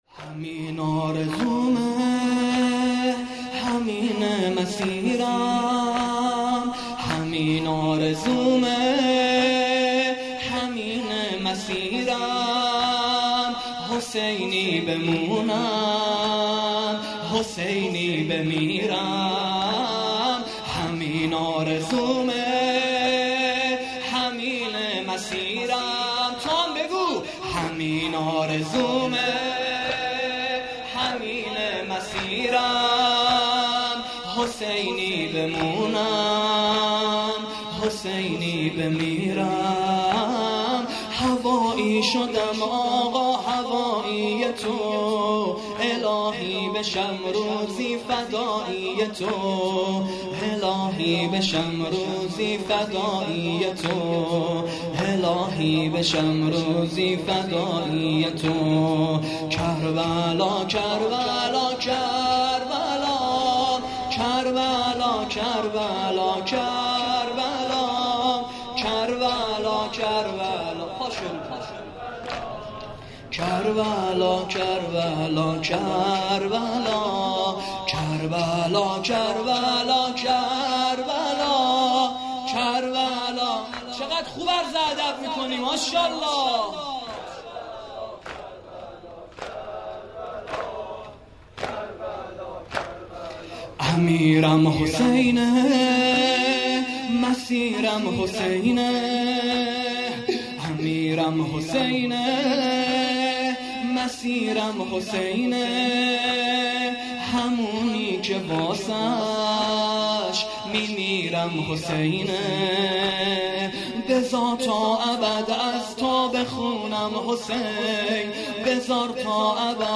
◼عزاداری دهه اول محرم - ۱۳۹۹/۶/۲